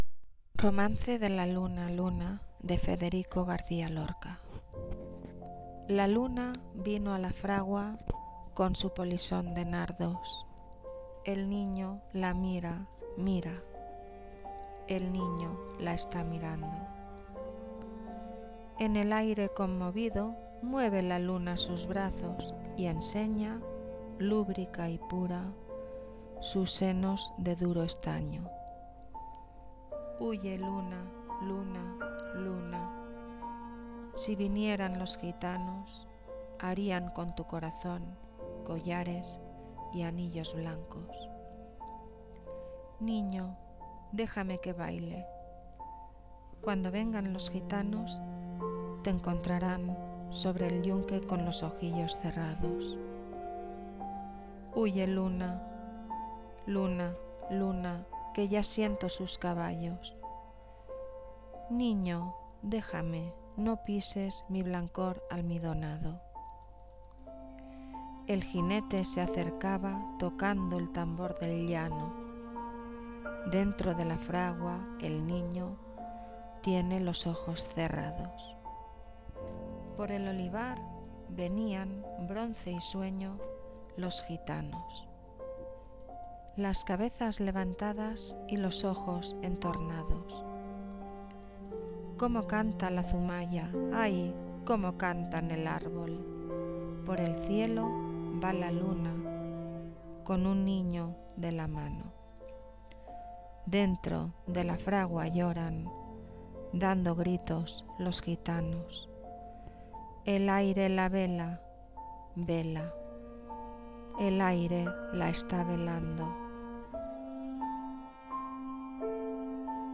Música de fondo de arvopartspiegelimspiegel-canalcomunidad-ivoox905129
poema
lectura
narración
Sonidos: Voz humana